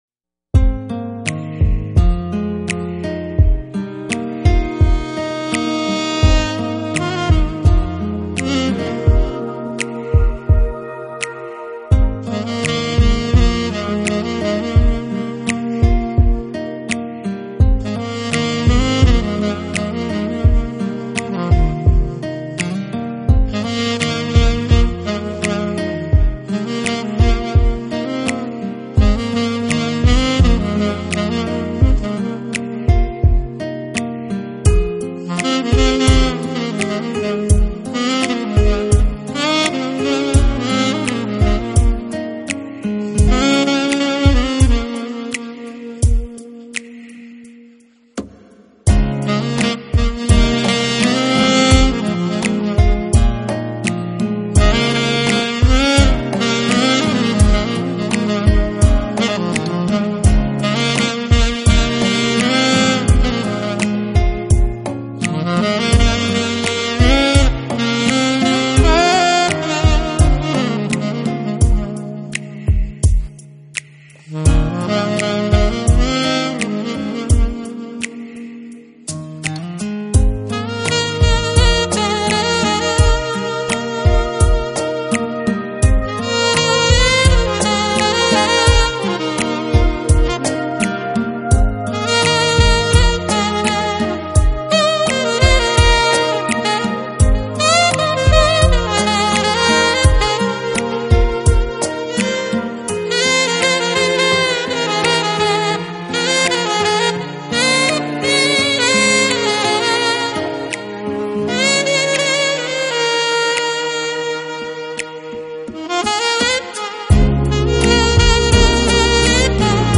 专辑风格：Smooth Jazz